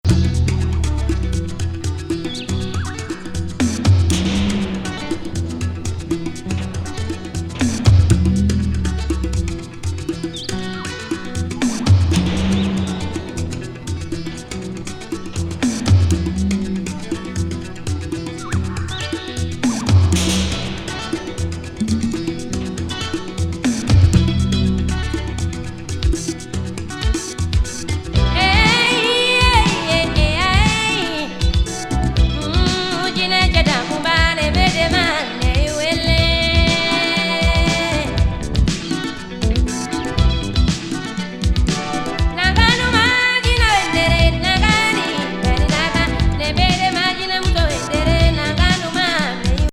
マリの女性シンガーのフレンチ・アフロNW87年作。
エレクトロニクス・スピリチュアル